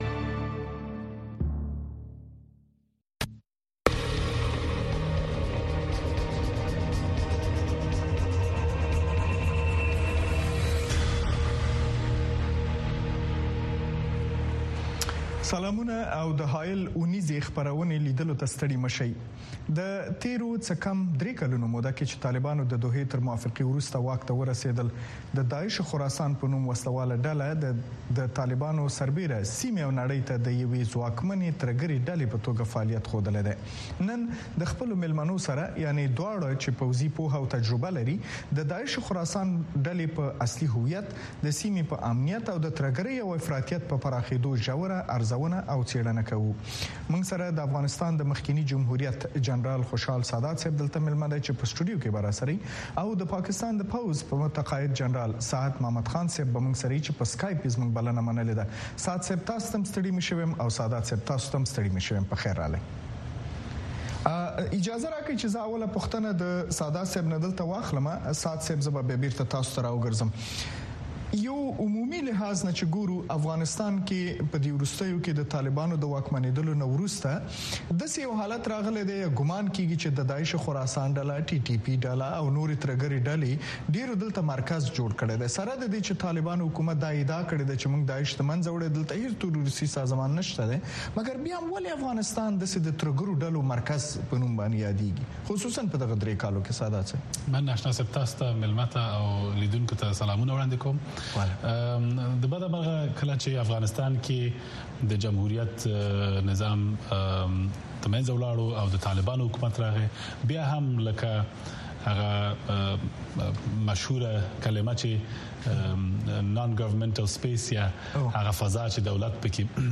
په دغه خپرونه کې د بېلابېلو اړخونو سره په مخامخ، ژورو او تودو بحثونو کې د افغانستان، سیمې، او نړۍ مهم سیاسي، امنیتي، اقتصادي، او ټولنیز موضوعات څېړل کېږي.